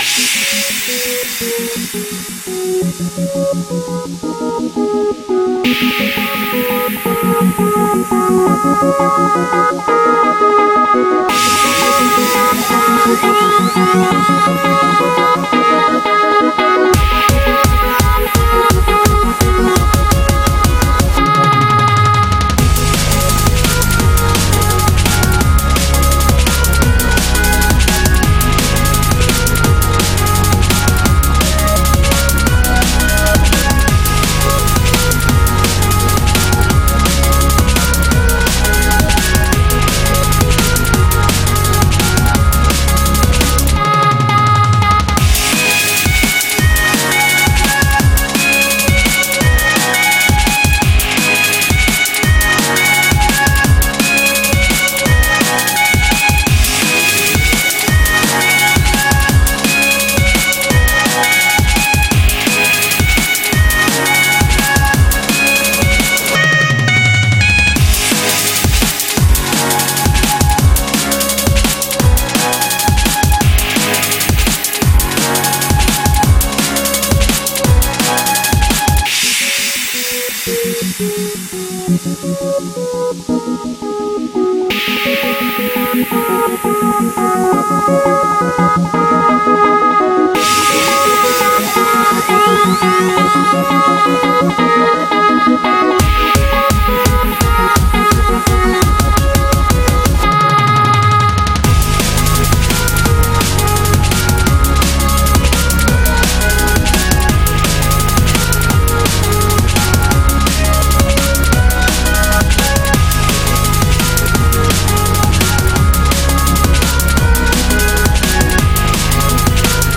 BPM170--1